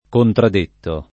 contradetto [ kontrad % tto ]